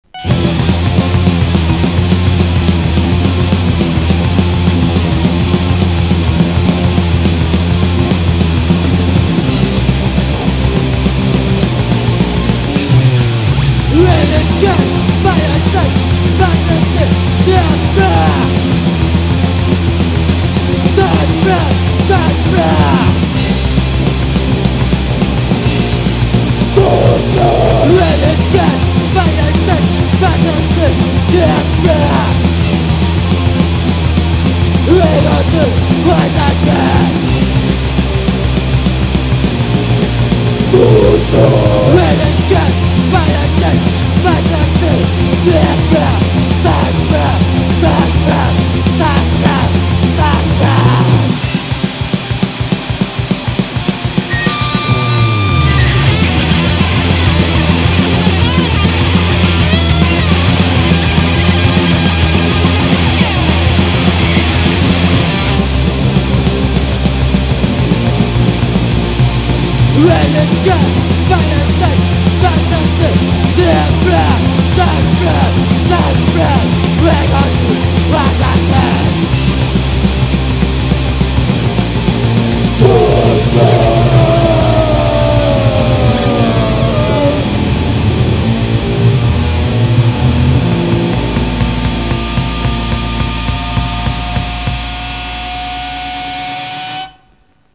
まぁその道の人以外はタダのノイズにしか聞こえないと思いますが。。